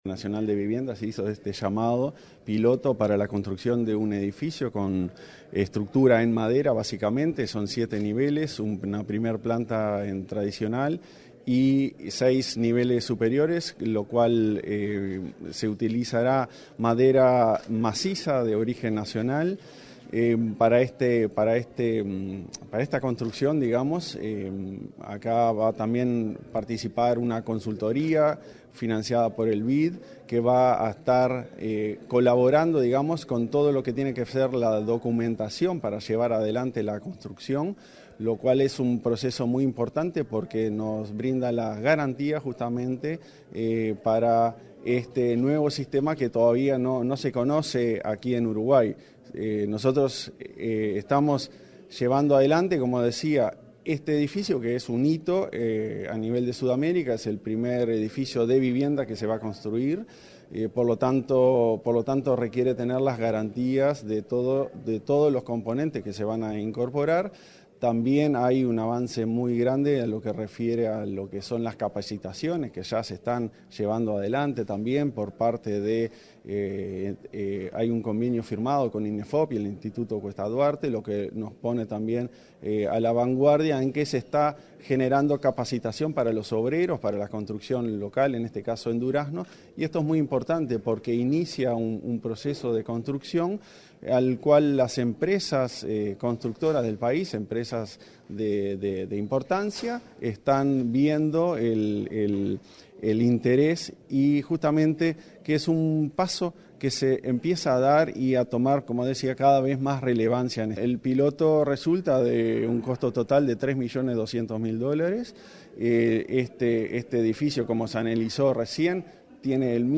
En rueda de prensa, el presidente de la Agencia Nacional de Vivienda, Klaus Mill dijo que además de bajar los costos de producción, también se trata de un tipo de construcción más sustentable ya que se reducen notoriamente los restos de obras y con ello la emisión de CO2.